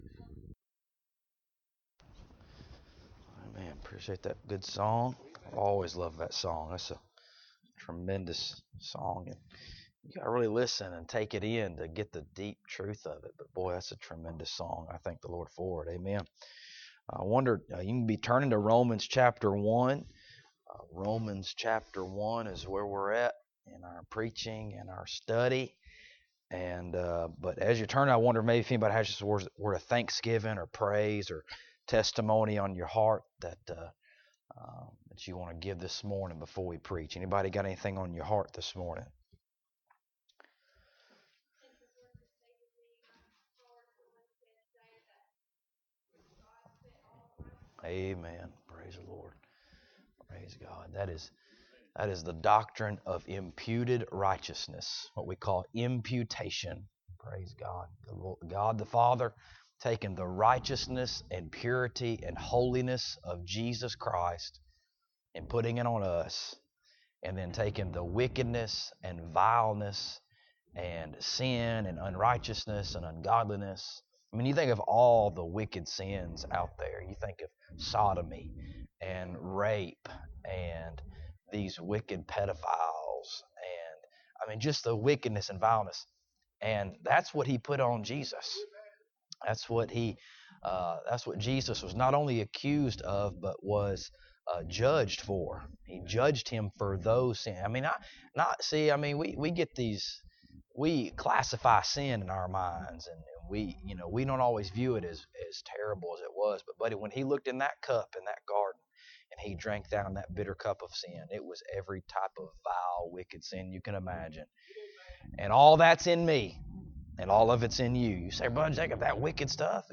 Romans Passage: Romans 1:14-20 Service Type: Sunday Morning Topics